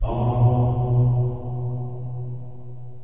Descarga de Sonidos mp3 Gratis: oomm.
reverberacion_1.mp3